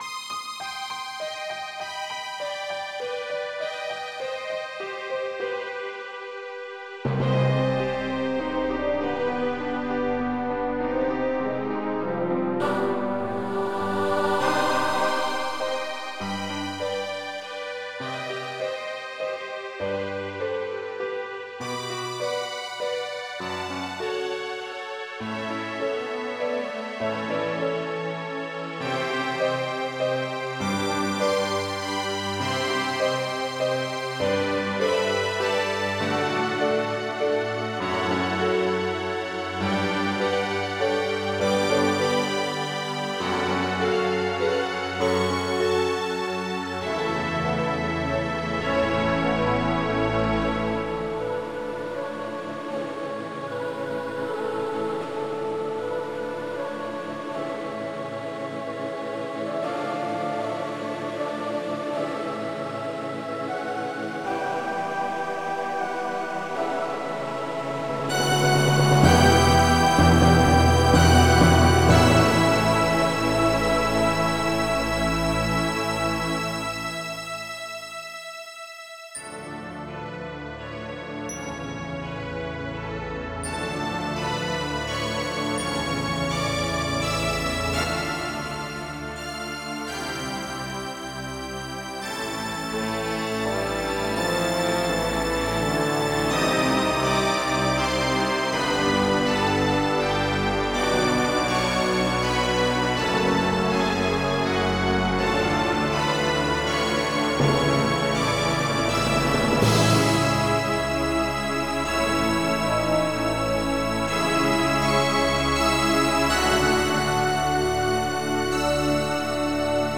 (NZ) 2001 March / 010207_0854.iso / FREE / CULT.ZIP / Zack47.da_ / Zack47.da ( .mp3 ) MIDI Music File | 2000-08-22 | 31KB | 2 channels | 44,100 sample rate | 8 minutes, 41 seconds Title untitled Type General MIDI